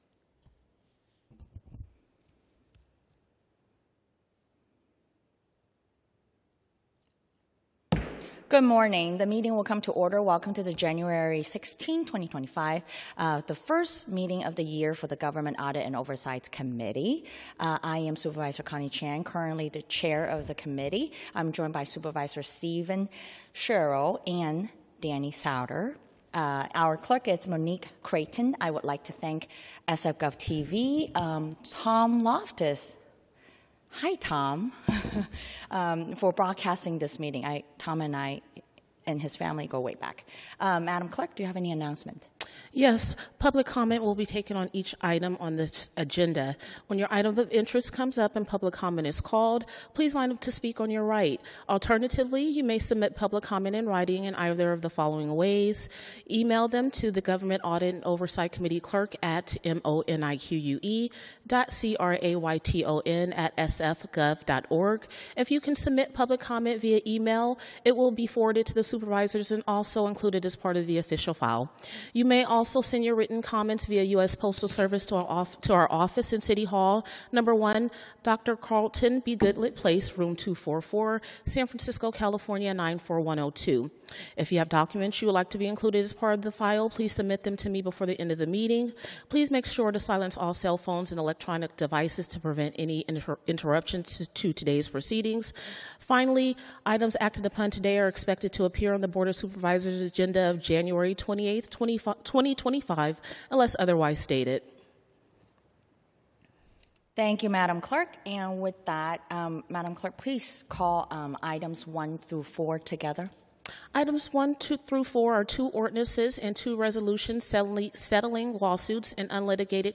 BOS - Government Audit and Oversight Committee - Regular Meeting - Jan 16, 2025